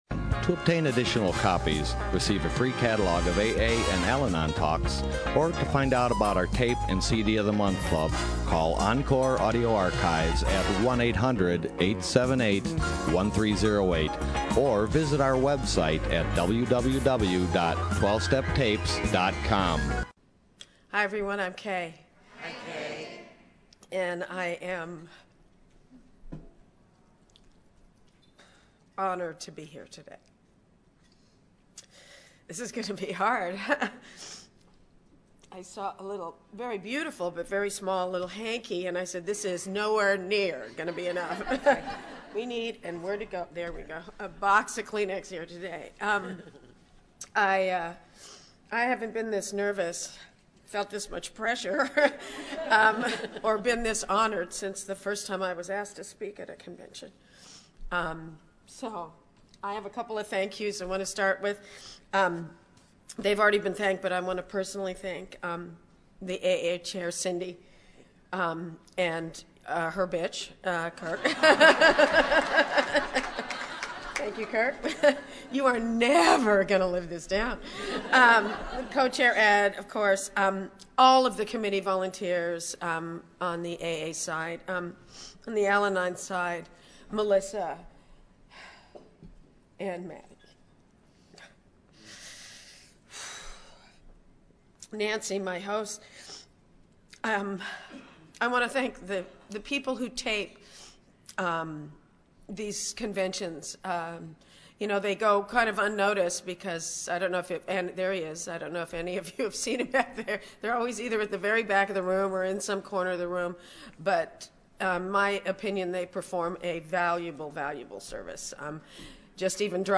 Orange County AA Convention 2012